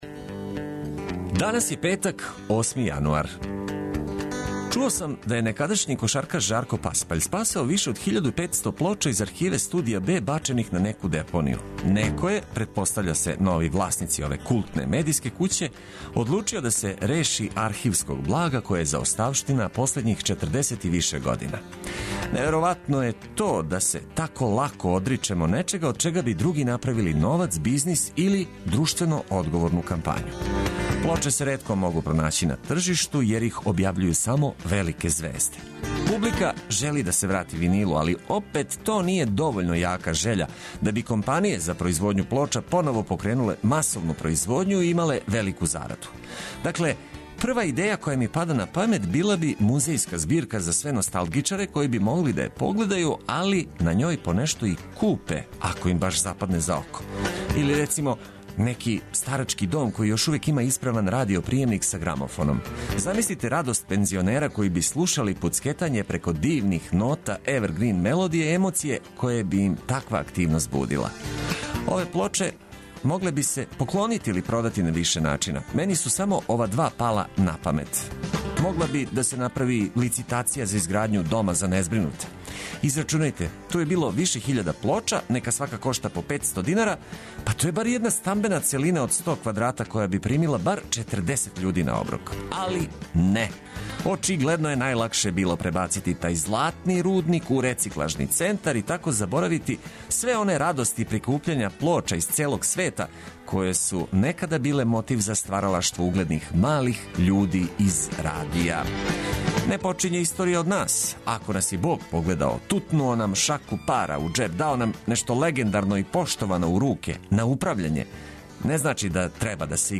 У сусрет викенду уз много корисних информација, одличну музику и ведро расположење.
Уредник и водитељ